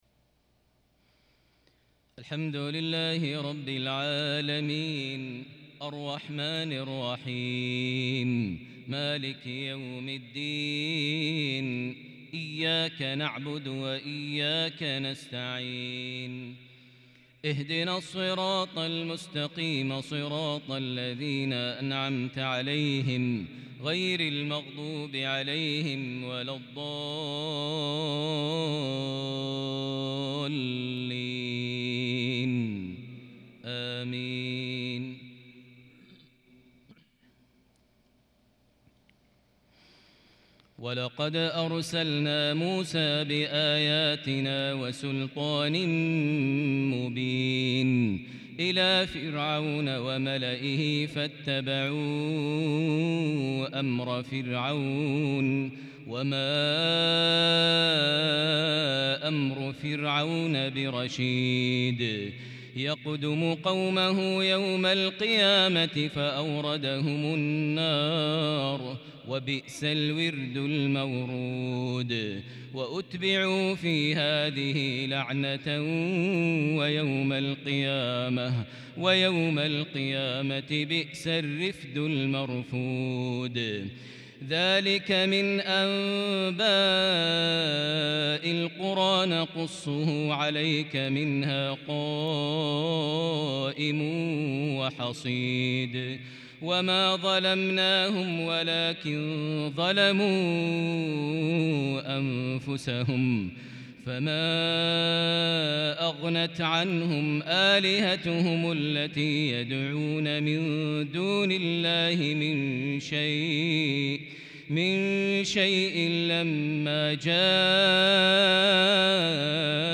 عشائية كردية فذه من سورة هود (96-114) | الجمعة 29 ذو القعدة 1442هـ > 1442 هـ > الفروض - تلاوات ماهر المعيقلي